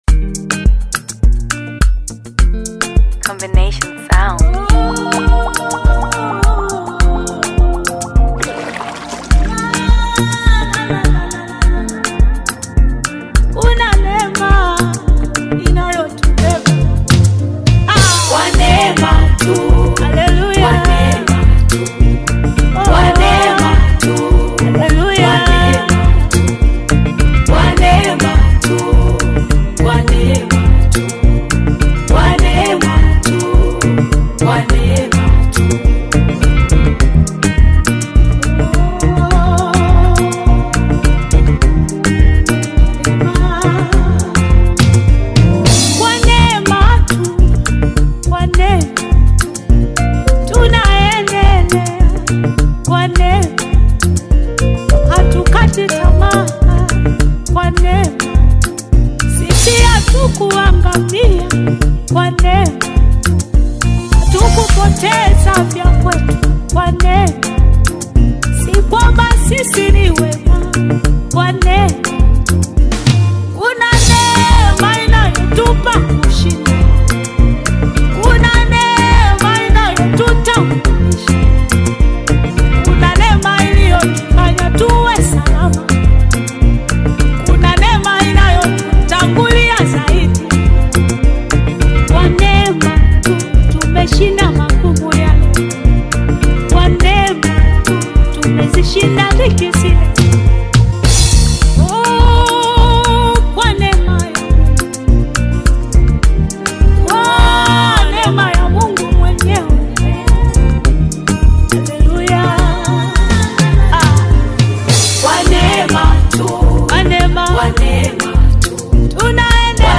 Foreign Artists - Gospel Songs Collection